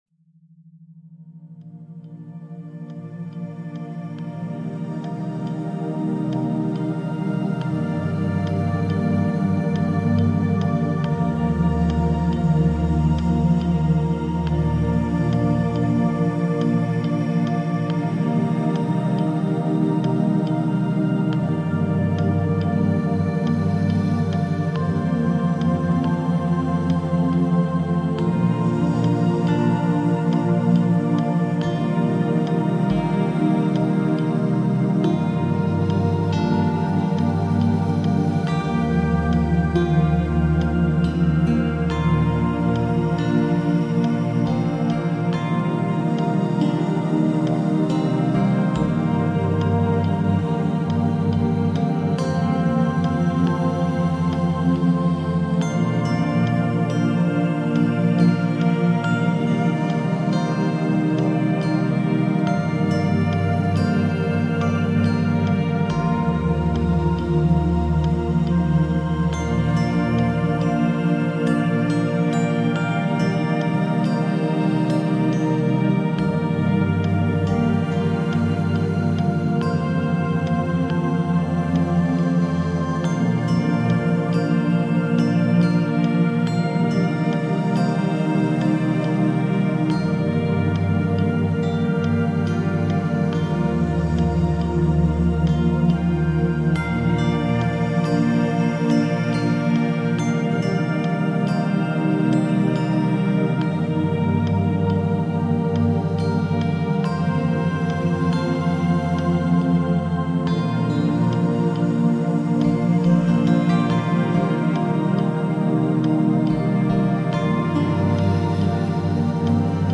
Sacral Chakra Meditation – 303 Hz Healing Sound for Emotional Flow